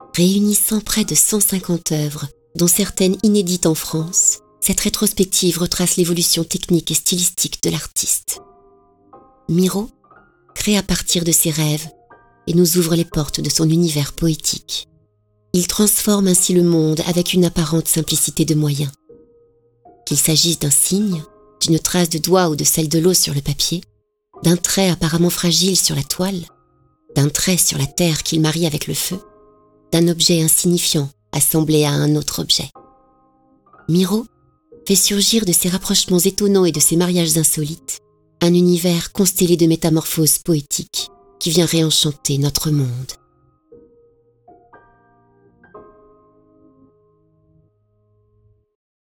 Documentaire / Narration